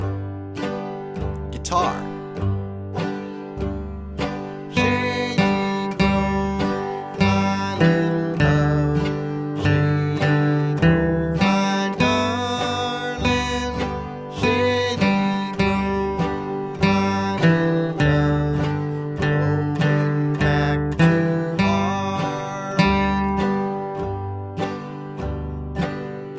Picking: Intro